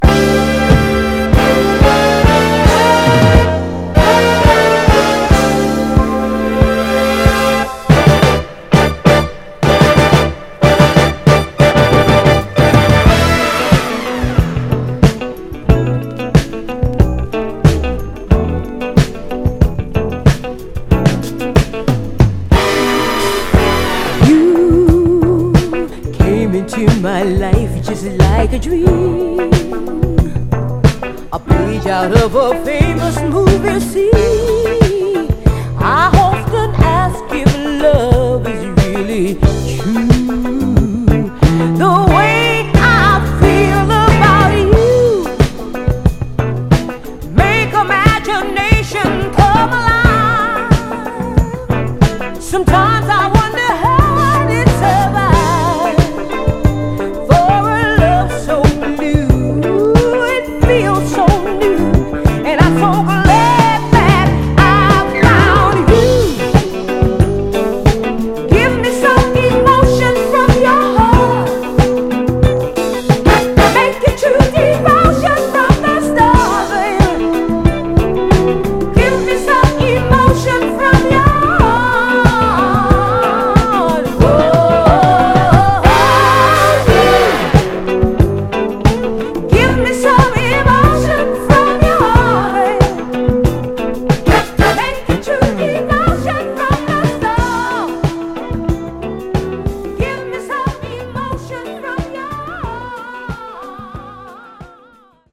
※試聴音源は実際にお送りする商品から録音したものです※